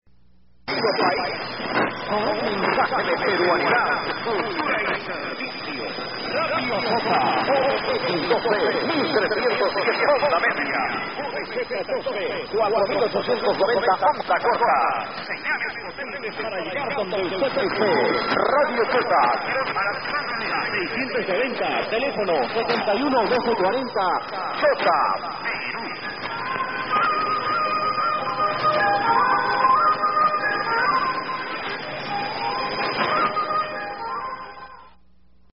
Radio Chota as I heard it in Quito, Ecuador on 4890 kHz in 1997: